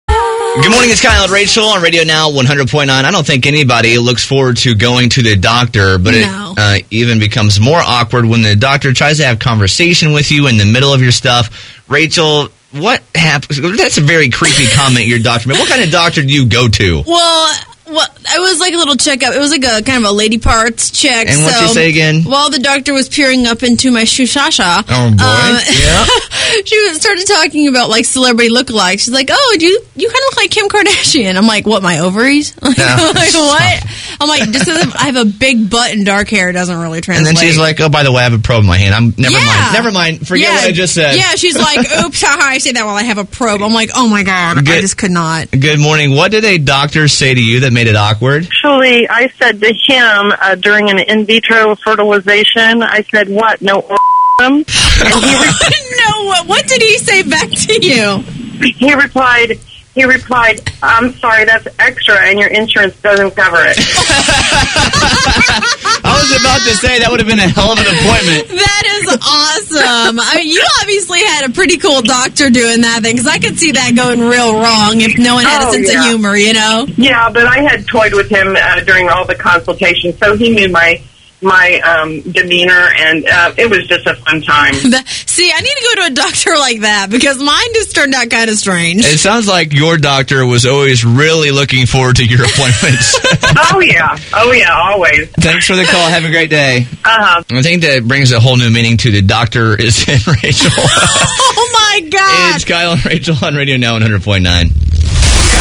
Mid exam, the doctor commented about how she looks like a celebrity! Listen to what happened and your calls on comments that made the doctor appointment awkward!